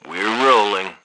sounds_colony_speech_move.dat
H_soldier1_24.wav